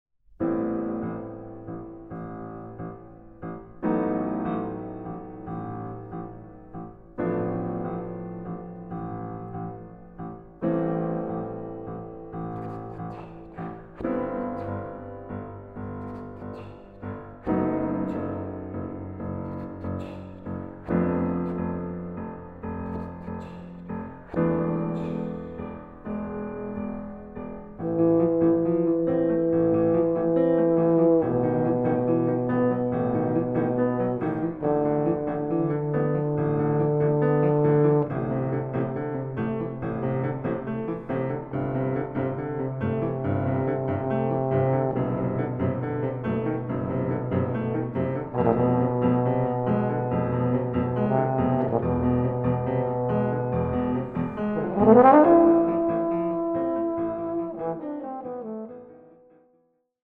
Arr. for Euphonium and Piano